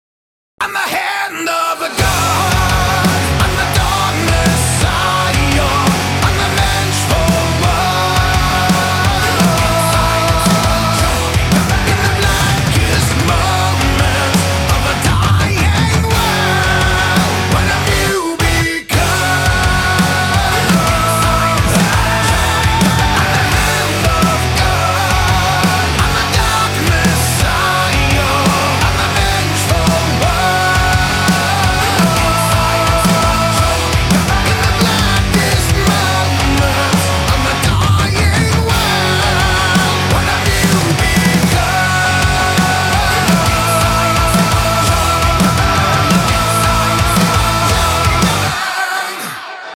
громкие
жесткие
мощные
брутальные
Драйвовые
nu metal
Hard rock
эпичные